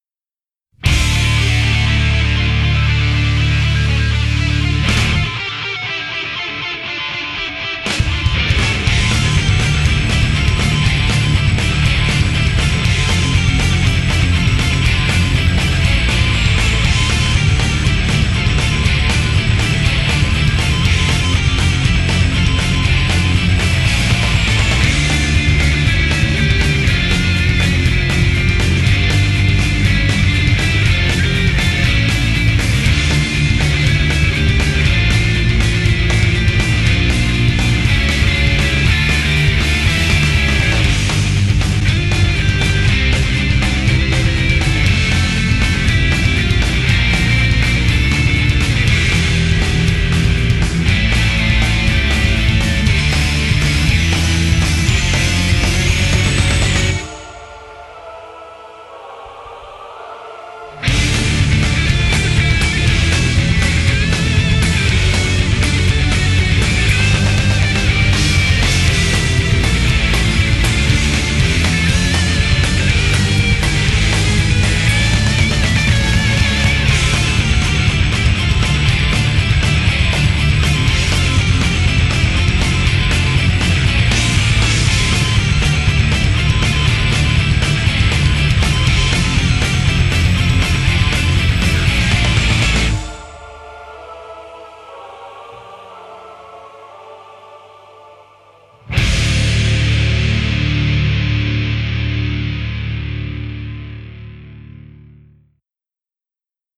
BPM60-240
Audio QualityPerfect (High Quality)